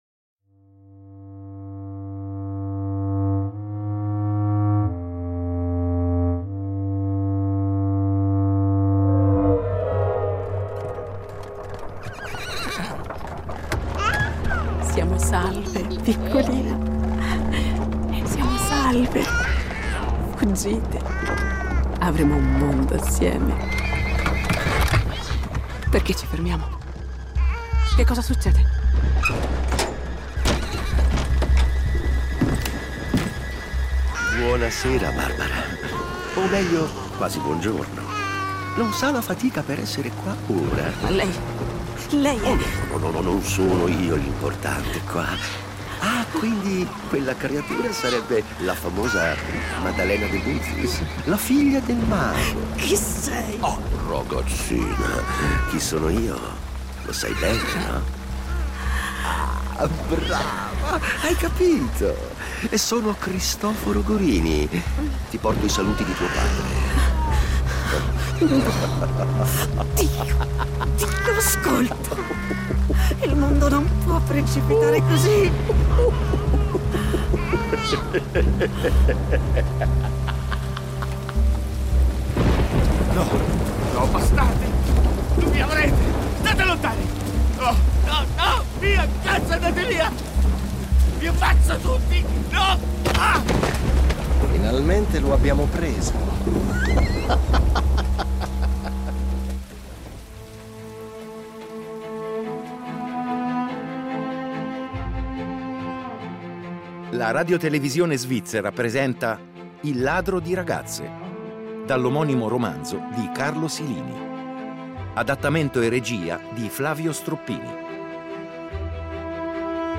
Un “film per le orecchie” che in un crescendo di continui colpi di scena, tradimenti, omicidi e con un sound design d’eccellenza, racconta dell’epica resistenza degli ultimi e della loro vendetta.